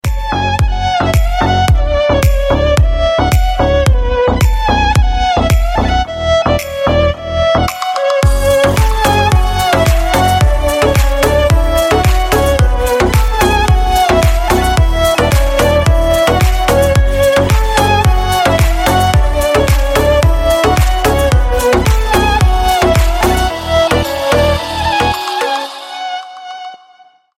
Восточные Рингтоны » # Рингтоны Без Слов
Танцевальные Рингтоны